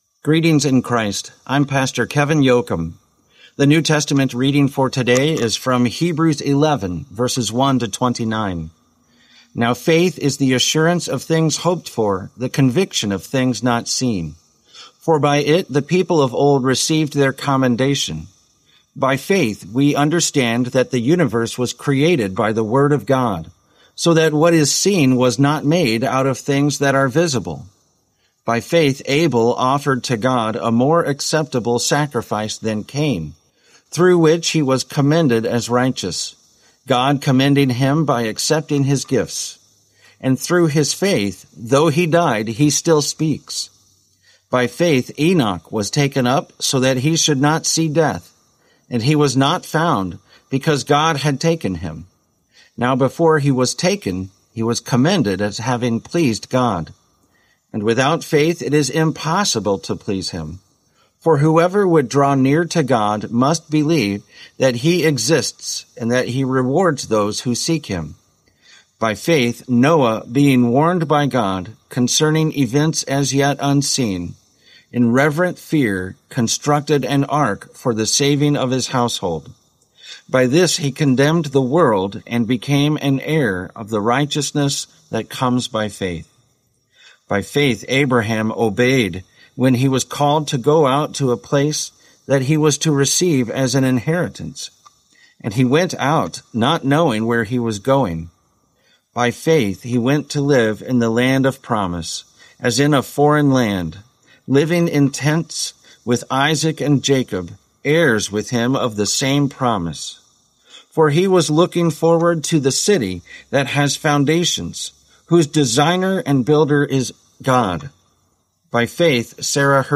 Morning Prayer Sermonette: Hebrews 11:1-29
Hear a guest pastor give a short sermonette based on the day’s Daily Lectionary New Testament text during Morning and Evening Prayer.